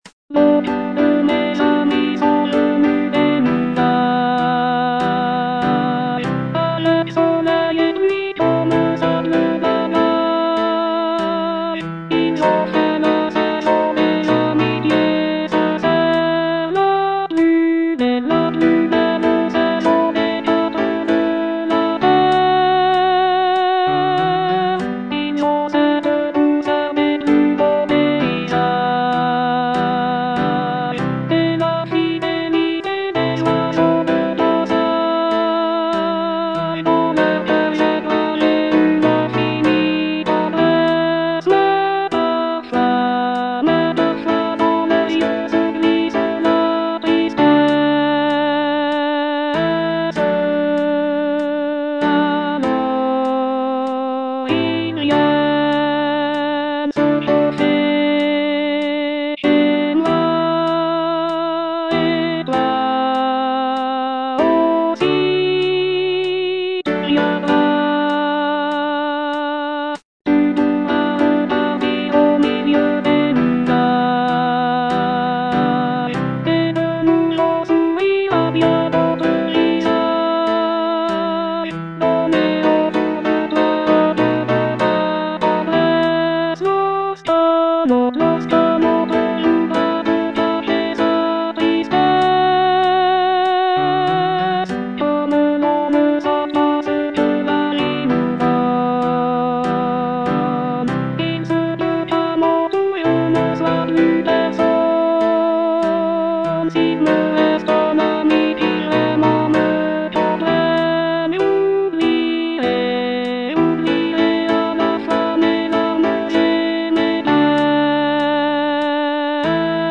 Alto II (Voice with metronome)
for choir